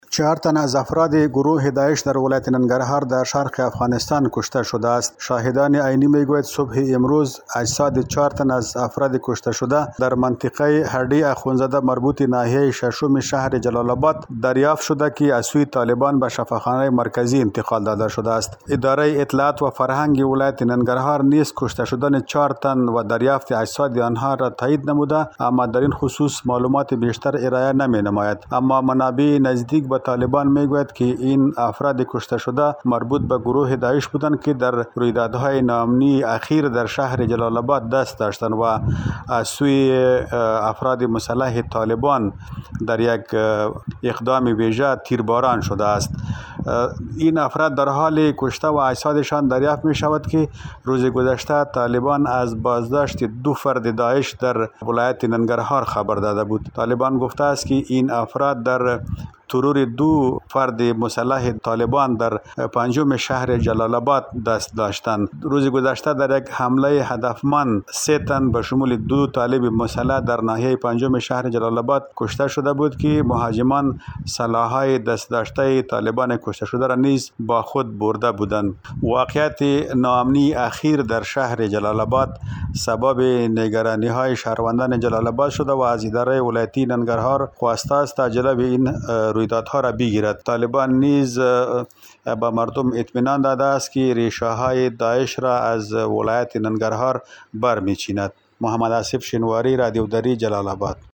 گزارش تکمیلی